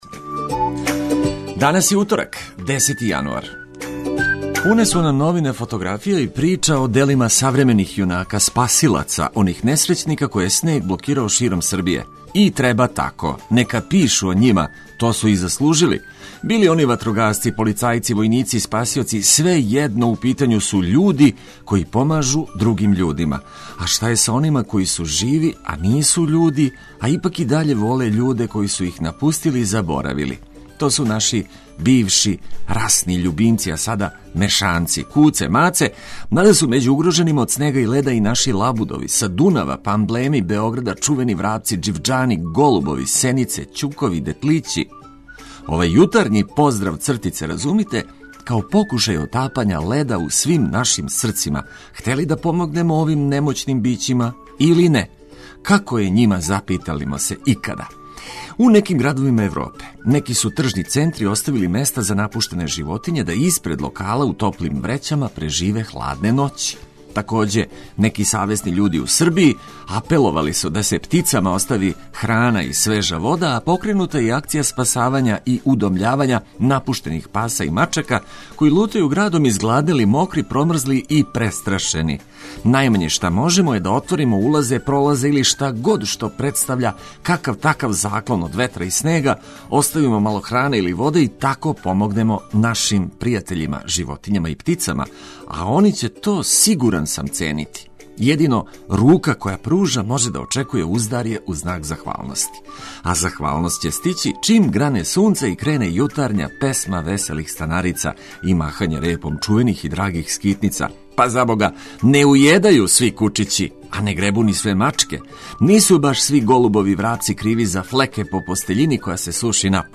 Током јутра најважније информације о временским и саобраћајним приликама у Србији током леденог таласа уз музику која ће олакшати устајање из топлог кревета.